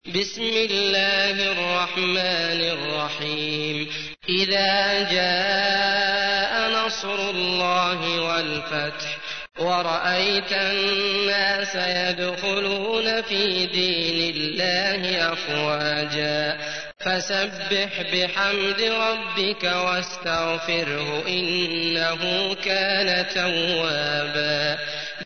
تحميل : 110. سورة النصر / القارئ عبد الله المطرود / القرآن الكريم / موقع يا حسين